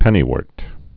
(pĕnē-wûrt, -wôrt)